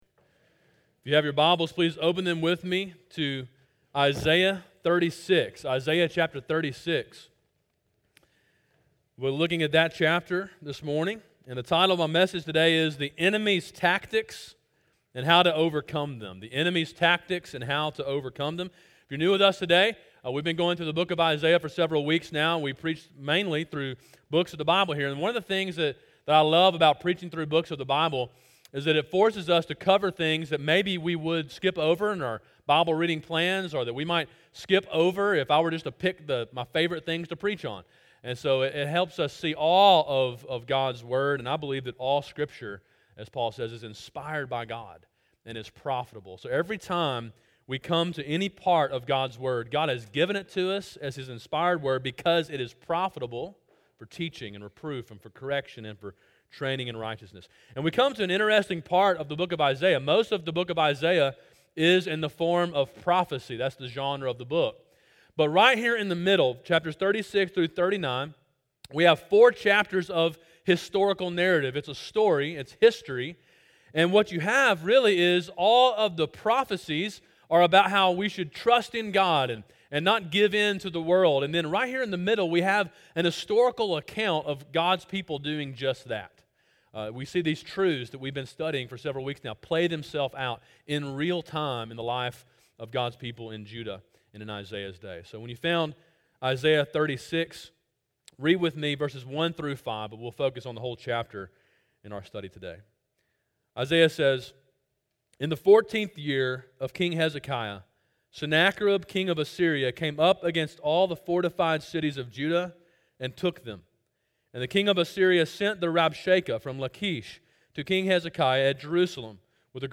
sermon5-28-17.mp3